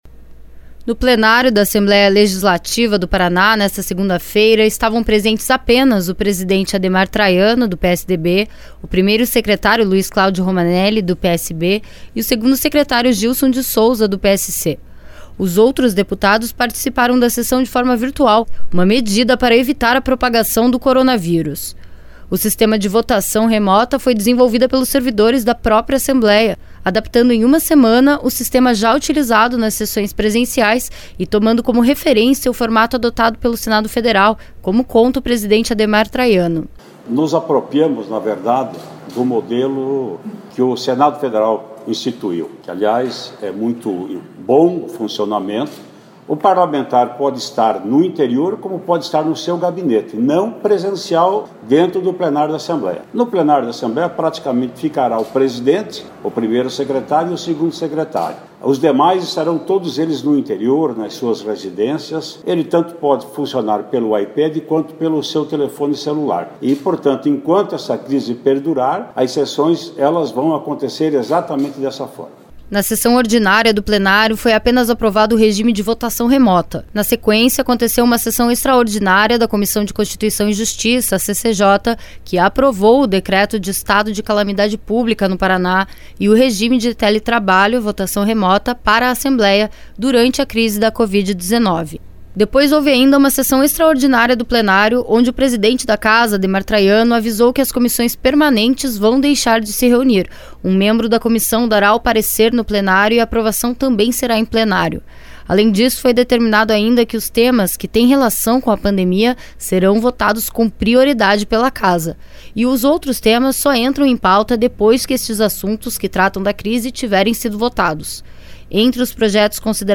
A Assembleia também adotou outras medidas de restrição de circulação de pessoas e higienização dentro da instituição, como explica o primeiro secretário Luiz Claudio Romanelli